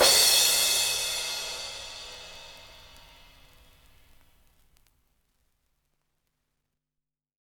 Crashes & Cymbals
Crash FinalVinyl.wav